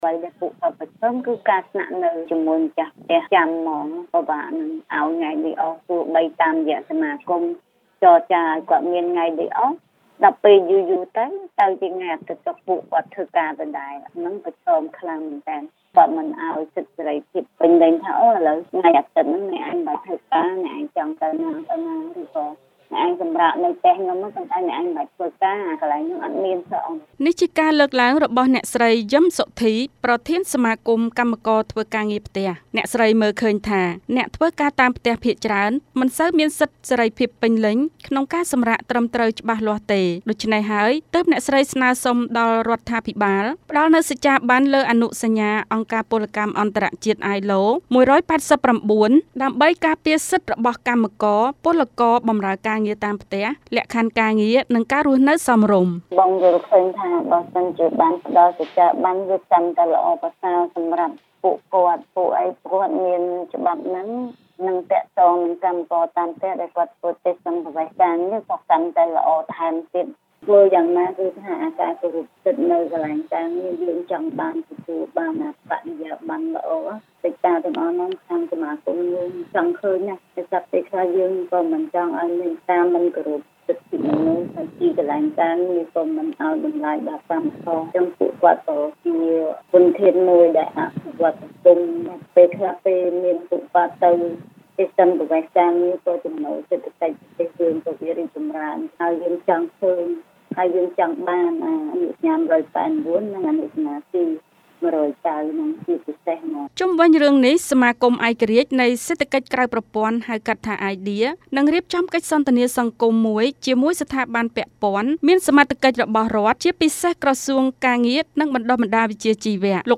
ជូនសេចក្តីរាយការណ៍